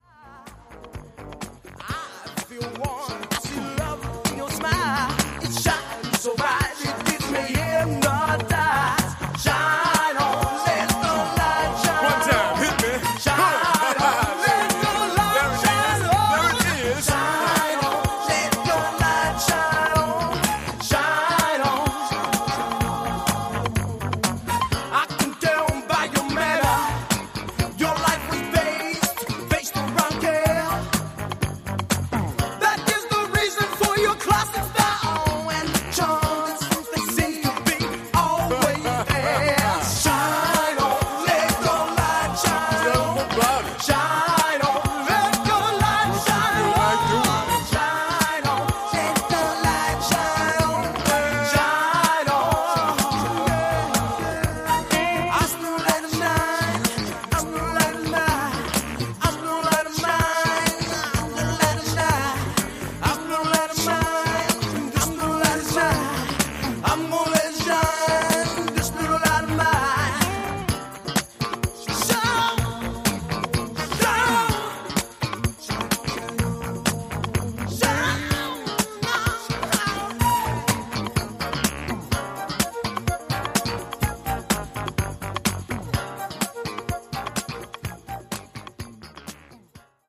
US Funk band